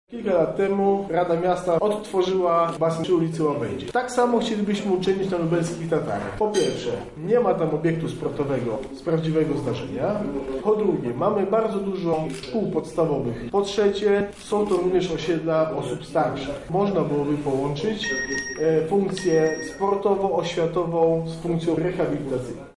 O poparciu dla budowy basenu mówi Przewodniczący Rady Miasta Piotr Kowalczyk